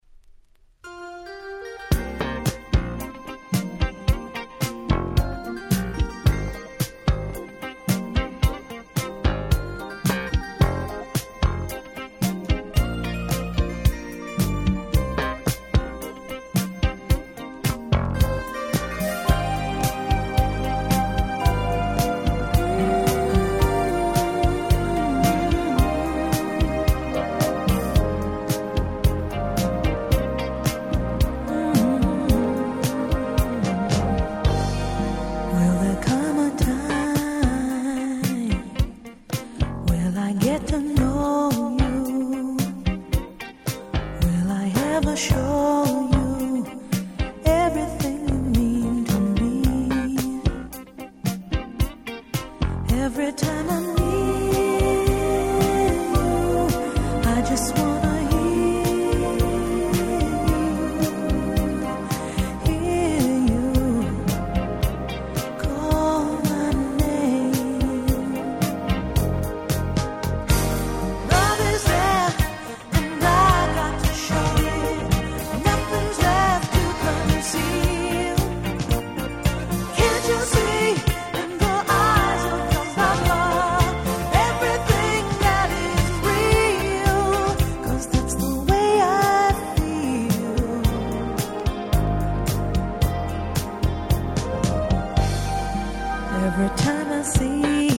81' Super Hit Disco/Dance Classic !!